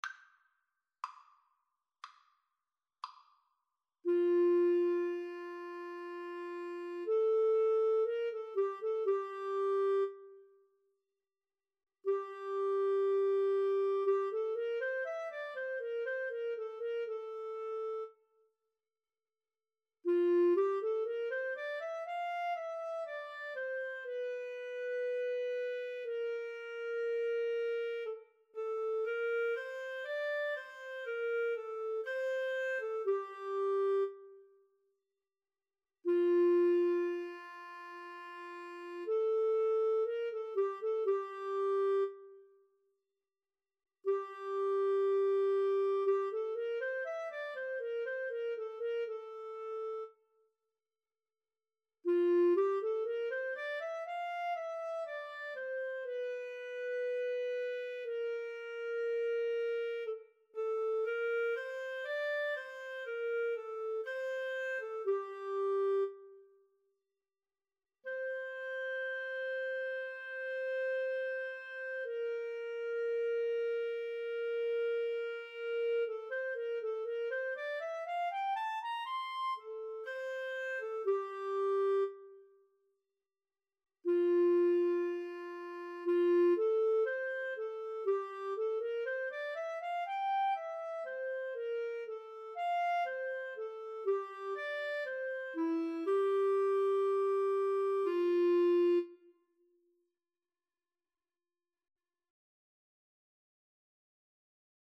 Classical (View more Classical Clarinet-Viola Duet Music)